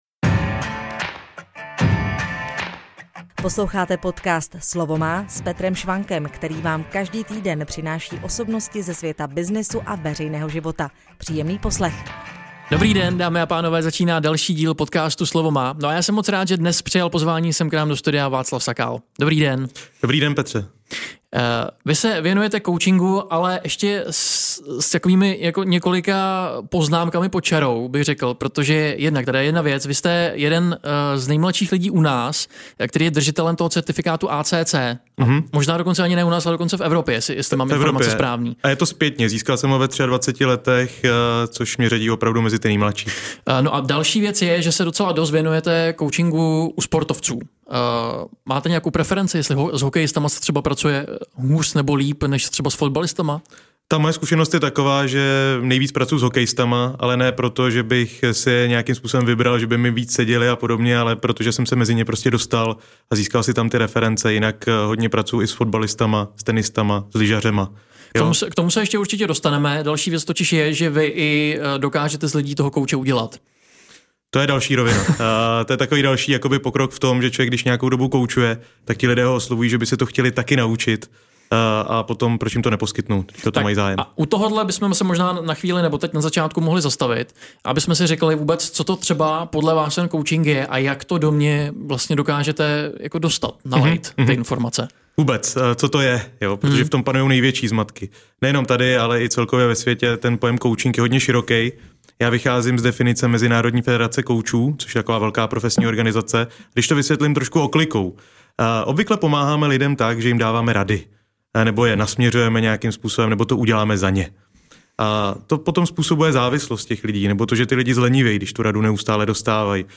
V rozhovoru popisuje, jak vlastně koučink vypadá a k čemu jsou například dobré vizualizace při práci s vrcholovými českými hokejisty.